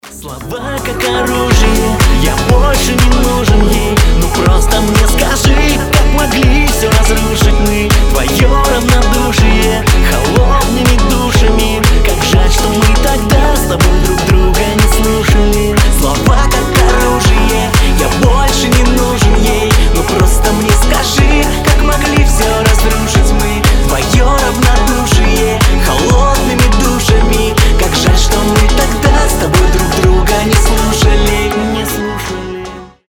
мужской голос
грустные
europop